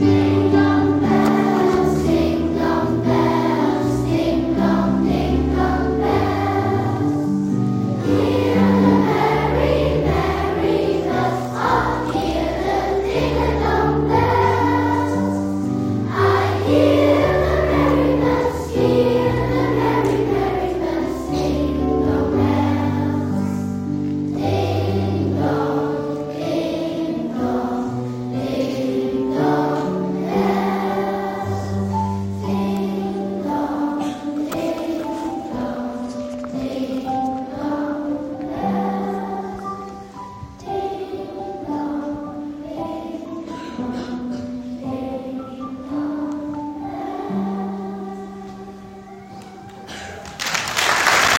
Weihnachtskonzert in der Turnhalle
In der weihnachtlich beleuchteten Turnhalle haben die Kinder der Klassen 3 & 4 ein kleines, stimmungsvolles Weihnachtskonzert gesungen.
Die Stimmen der 200 Chorkinder zauberten dem Publikum ein Lächeln und manchen sogar eine Träne der Rührung ins Gesicht.